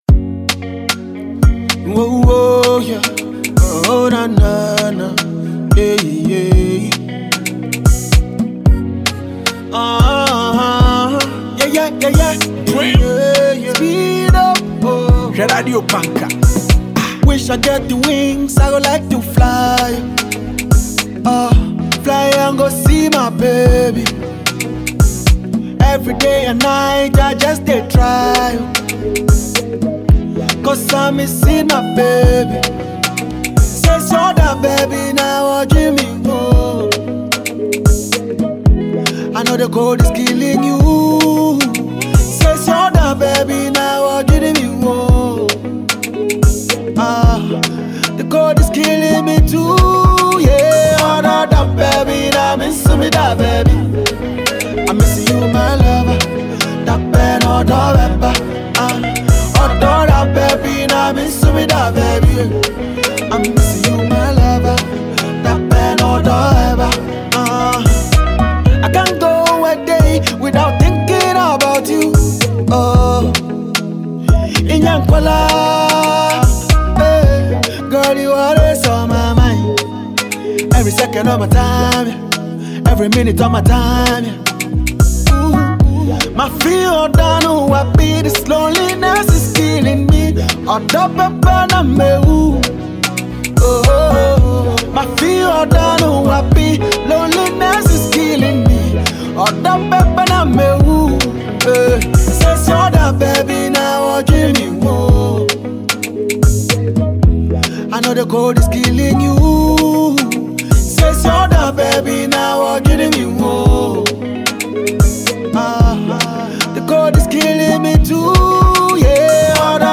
Ghana MusicMusic